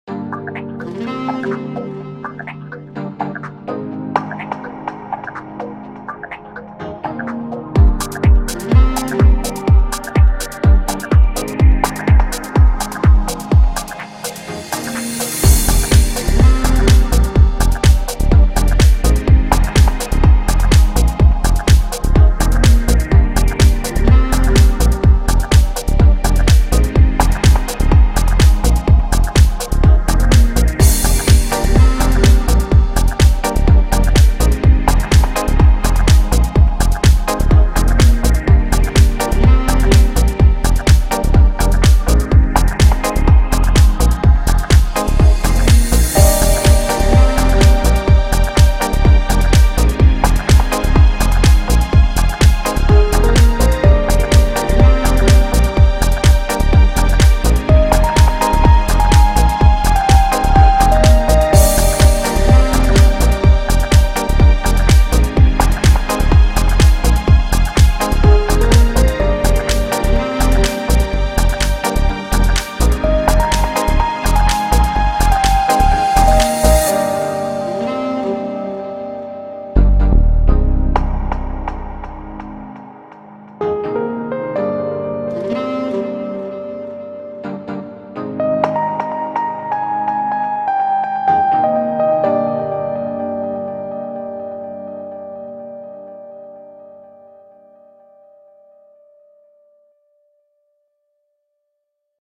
BPM125